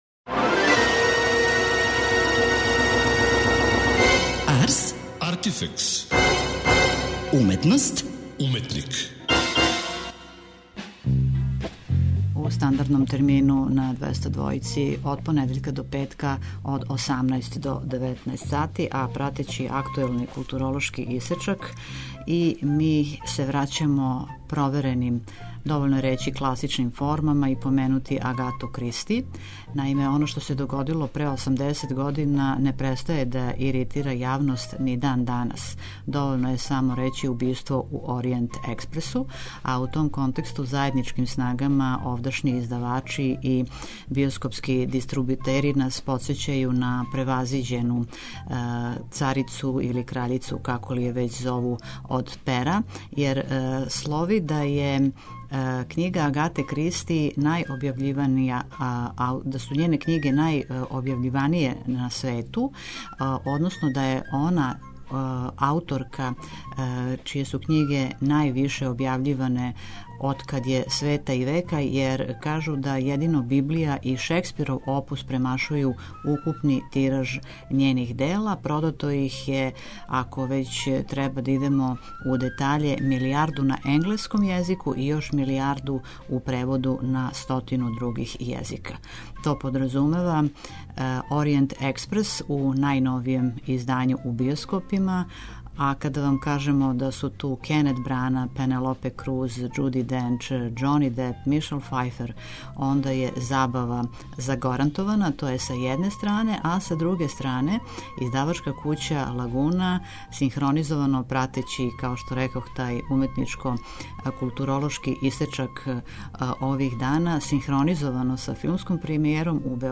преузми : 27.55 MB Ars, Artifex Autor: Београд 202 Ars, artifex најављује, прати, коментарише ars/уметност и artifex/уметника.